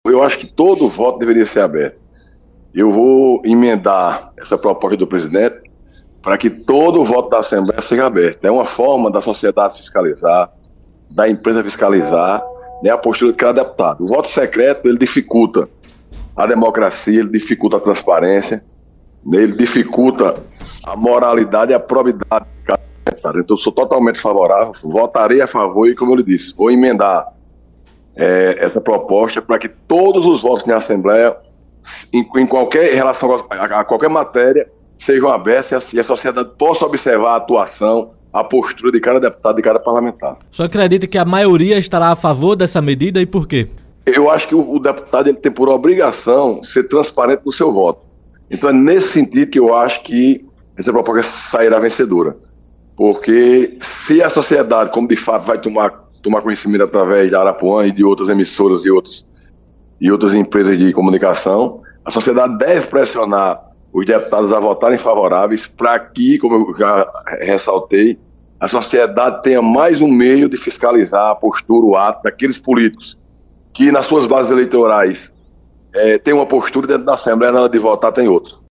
Em entrevista à Rádio Arapuan FM, Virgolino disse que votará a favor da mudança na eleição da Mesa Diretora, mas defendeu que alteração seja estendida para demais votações.